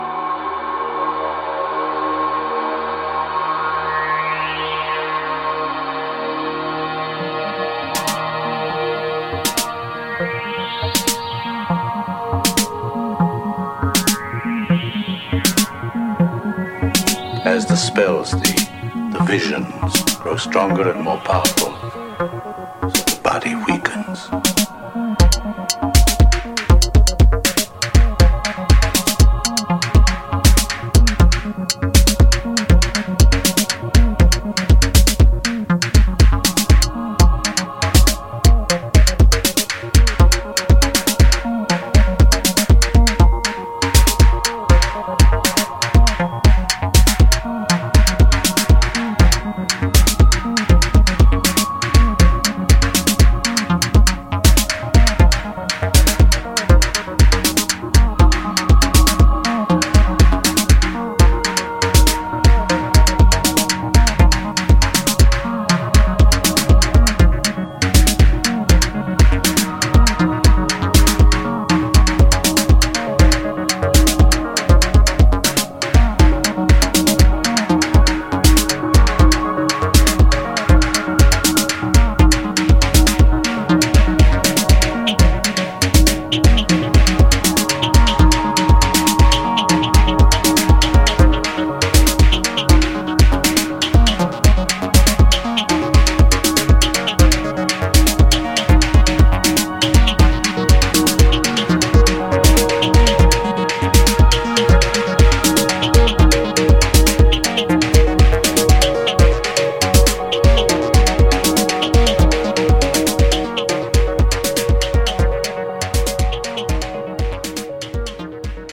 Deeply atmospheric and functioning in multiple realms